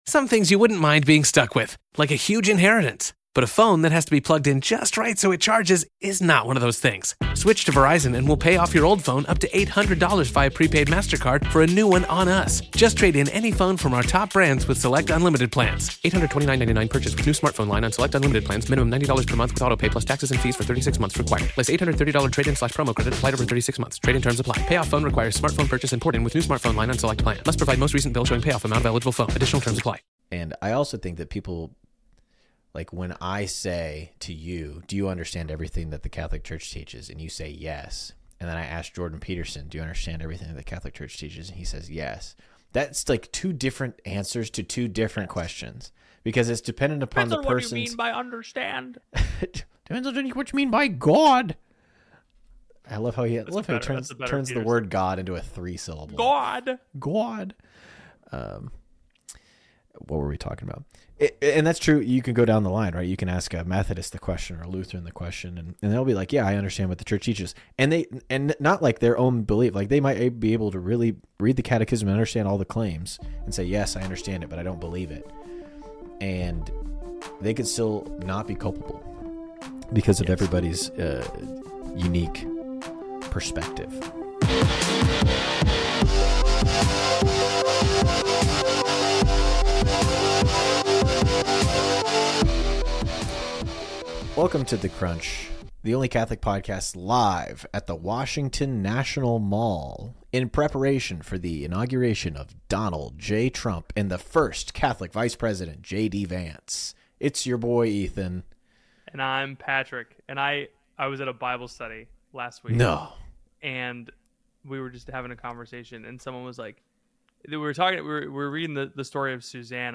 A Comedy Podcast for Young Catholics.